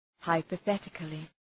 hypothetically.mp3